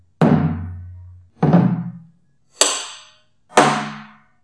Nghệ nhân có thể đánh ra nhiều âm khác nhau trên nhiều điểm ở trống: Tùng: là đánh vào mặt giữa trống. Rụp: là đánh hai dùi ở mặt trống.
Cắc: là đánh vào tang gỗ.
Tang: là đánh vào rìa mặt trống.
Tùng-Rụp-Cắc-Tang: Ví dụ: (385-006m)